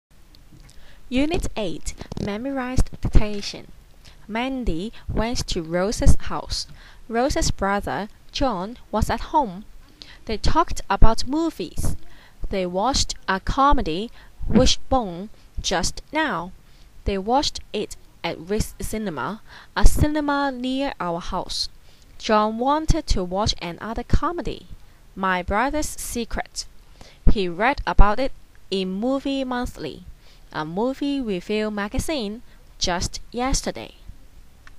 Memorized Dictation Unit 8.wma